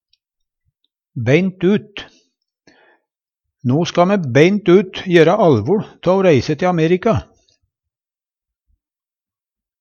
Tilleggsopplysningar L i allvoL er lyden mellom r og L